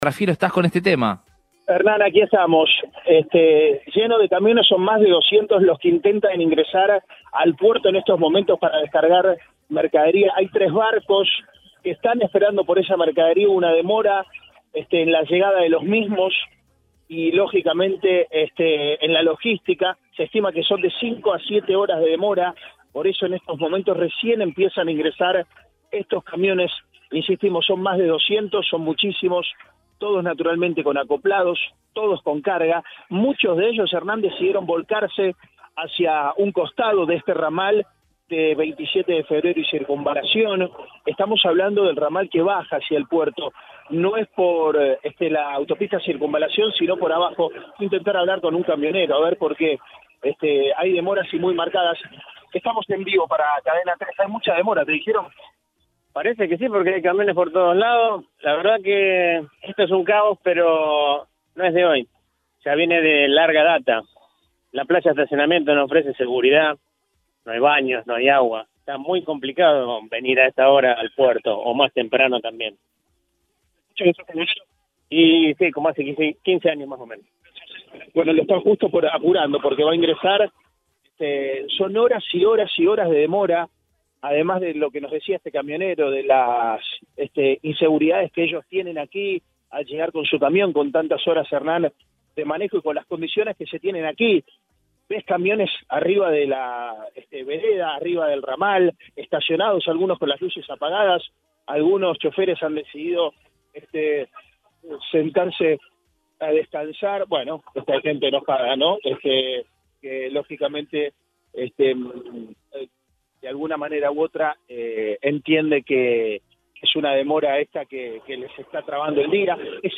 Según cuentan camioneros en el lugar al móvil de Cadena 3 Rosario la demora es de entre 5 a 7 horas.